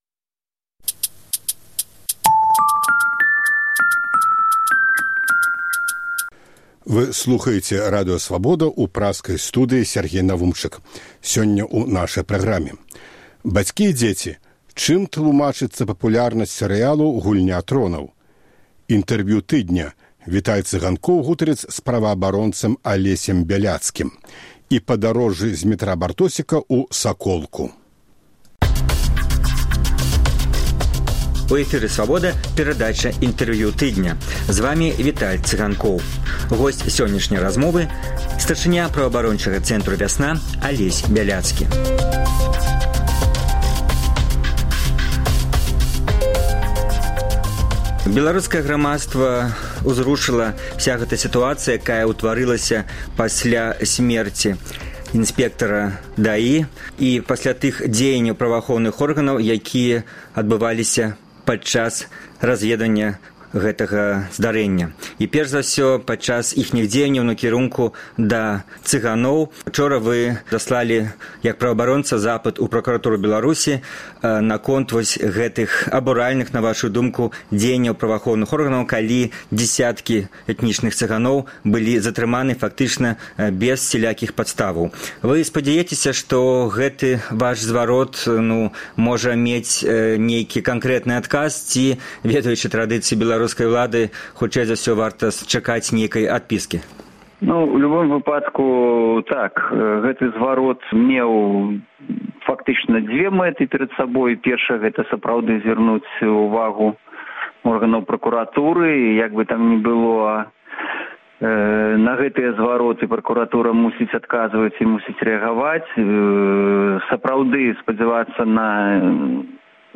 Чым тлумачыцца папулярнасьць сэрыялу “Гульня тронаў” Інтэрвію тыдня